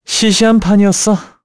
Esker-Vox_Victory_kr.wav